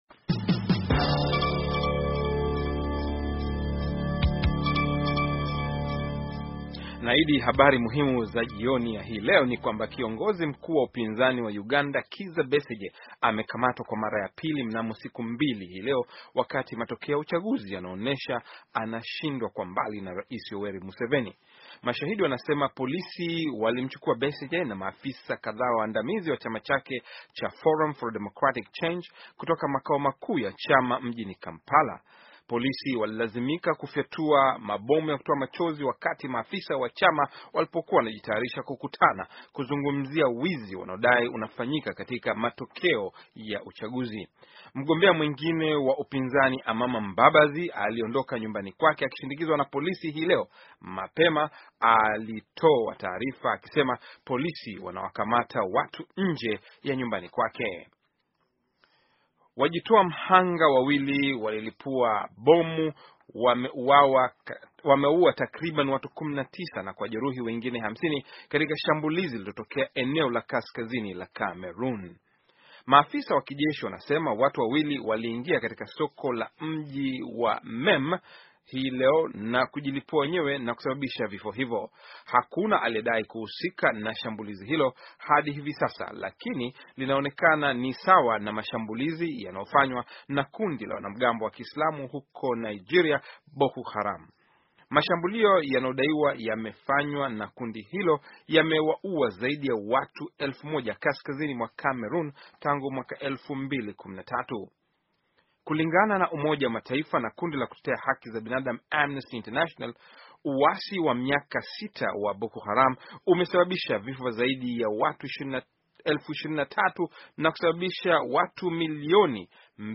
Taarifa ya habari - 6:48